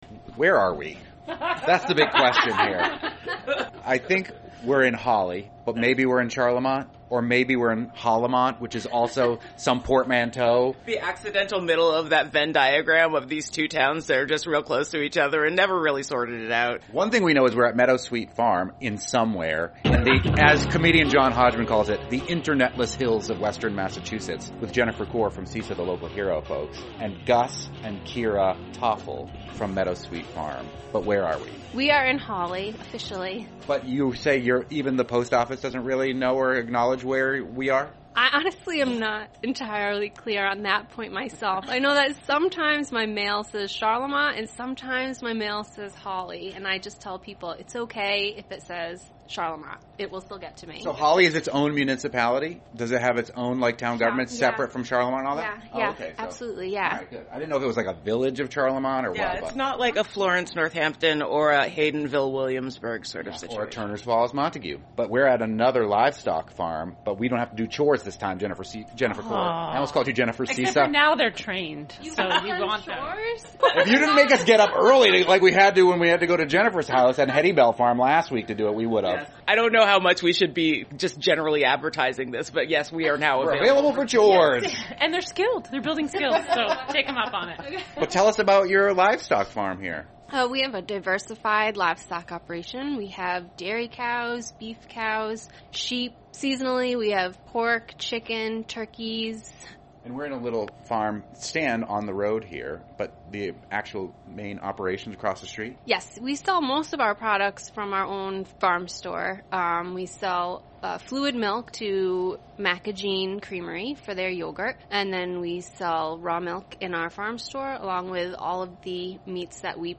"Fabulous 413" interviews